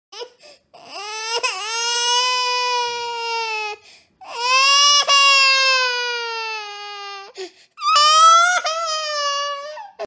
دانلود آهنگ گریه بچه 1 از افکت صوتی انسان و موجودات زنده
جلوه های صوتی
دانلود صدای گریه بچه 1 از ساعد نیوز با لینک مستقیم و کیفیت بالا